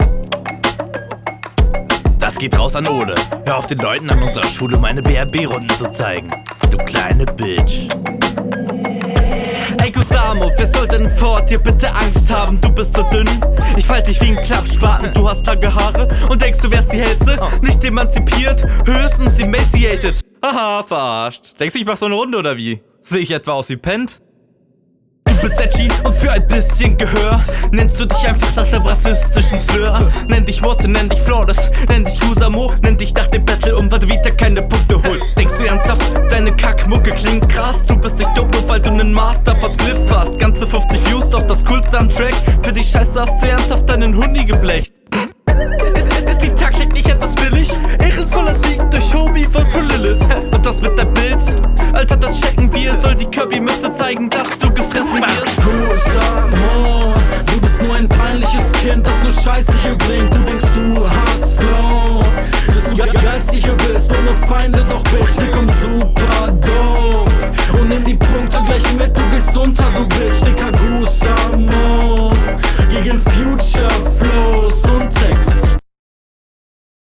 hook ist catchy aber rest ist mir zu abgehackt geflowt